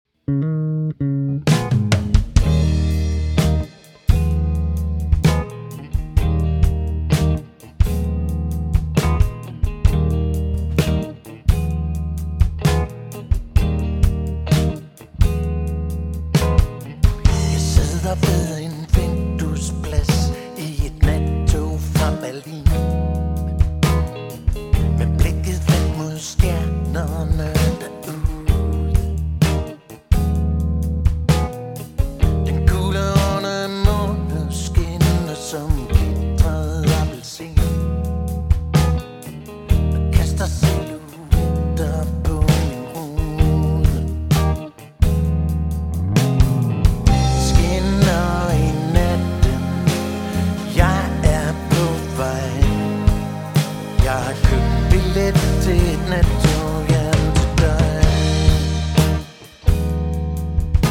• Beat
• Pop
Saxofon
Guitar
Trommer
Vokal